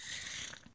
toadette_snore.ogg